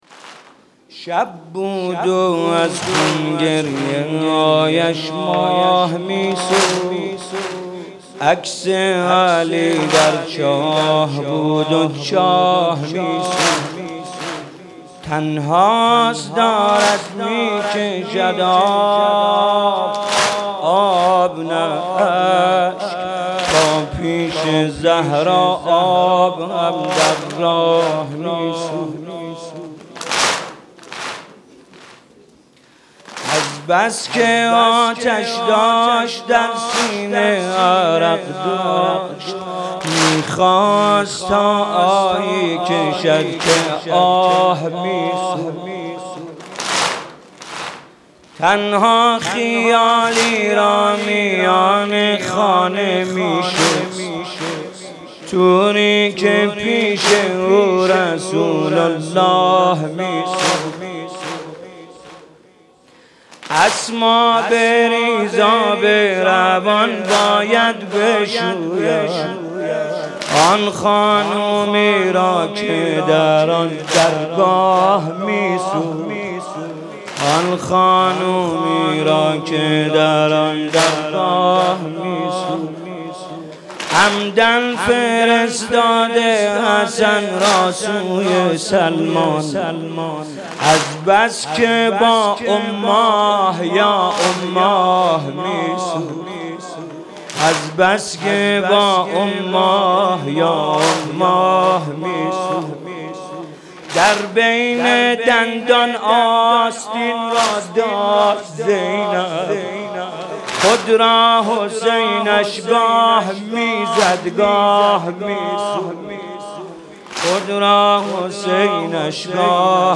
تولید شده: هیئت مکتب الزهرا حسین طاهری
مداحی_شهادت حضرت زهرا